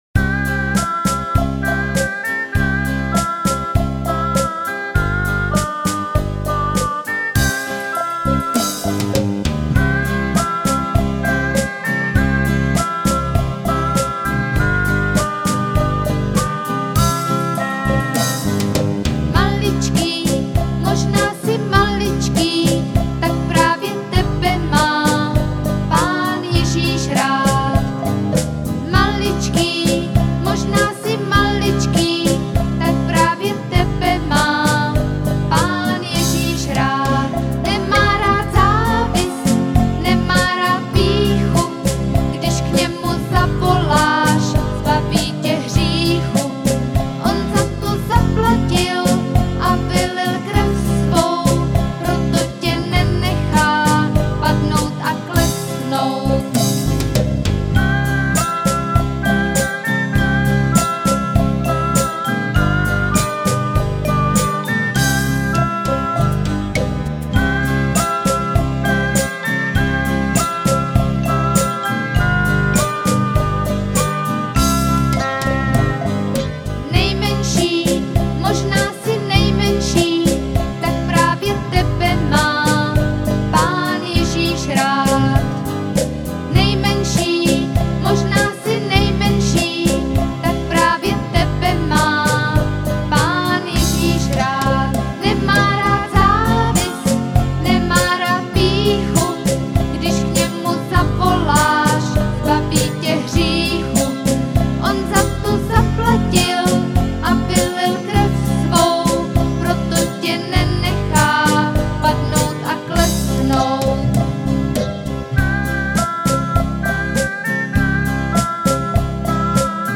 Křesťanské písně
Písničky pro děti
nejoblíbenější dětská ukazovačka - česky